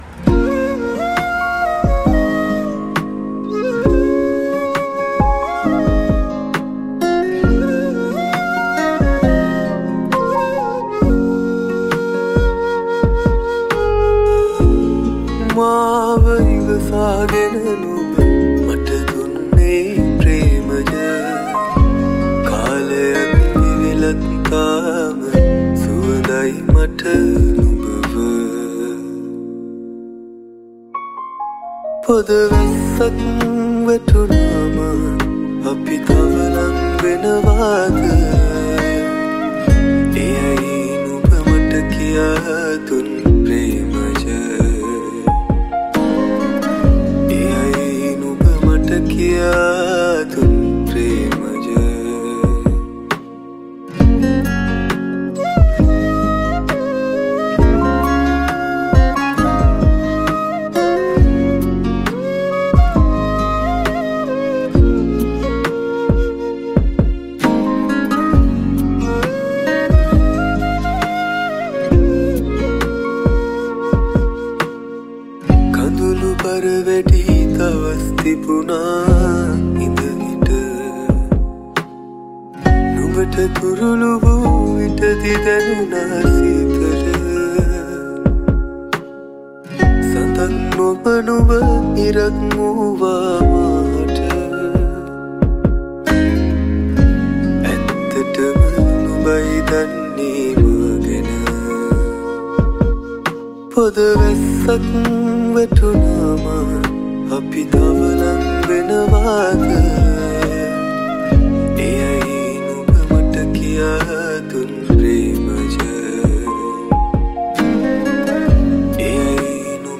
Flute
Guitars